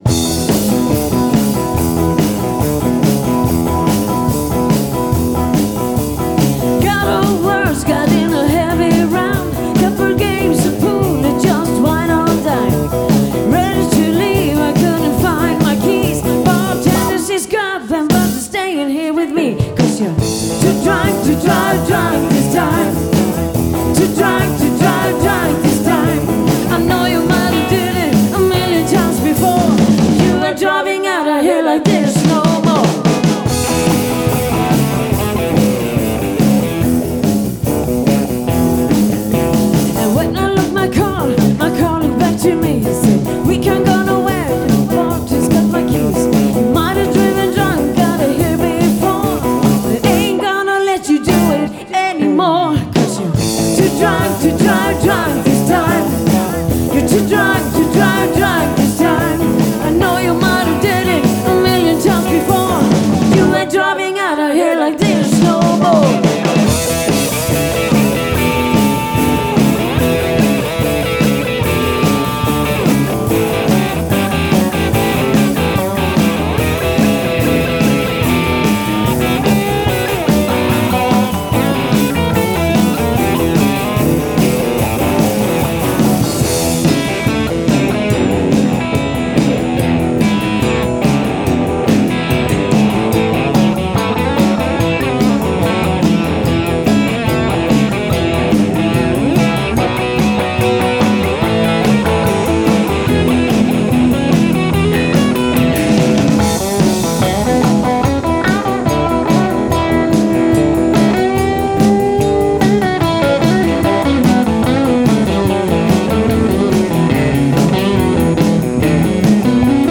• Coverband
• Bluesband